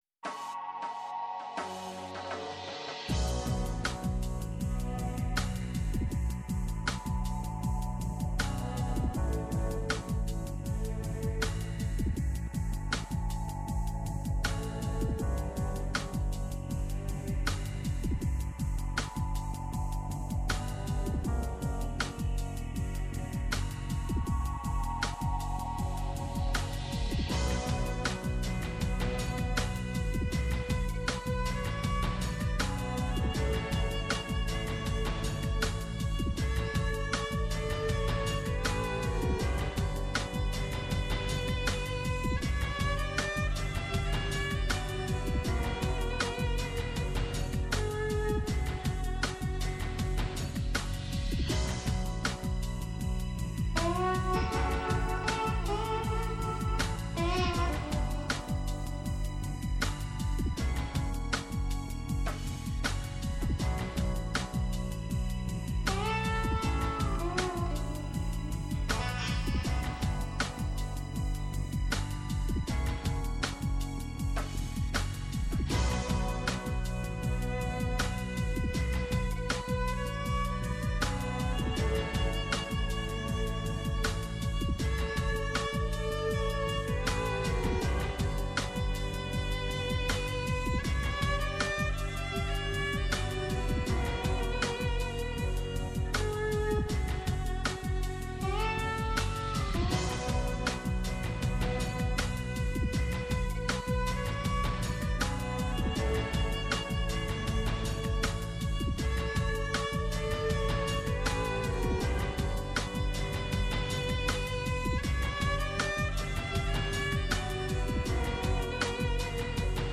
Η δεσμευτικότητα της τροπολογίας της Βουλής των Αντιπροσώπων των ΗΠΑ για την επιβολή αυστηρών όρων στη τυχόν πώληση F16 στην Τουρκία, οι εσωτερικές παρενέργειες στην αμερικανική κοινωνία με τις επιπτώσεις των αποφάσεων του Ανωτάτου Δικαστηρίου για τις εκτρώσεις και σειρά άλλων κρίσιμων θεμάτων και η αποτίμηση της περιοδείας Μπάιντεν σε Ισραήλ και Σ.Αραβία ήταν τα ζητήματα που εξετάστηκαν στο β΄ μέρος της εκπομπής, Καλεσμένος ήταν ο Δημήτρης Καιρίδης, Καθηγητής Διεθνών Σχέσεων στο Πάντειο, ειδικός επί της αμερικανικής πολιτικής και βουλευτής.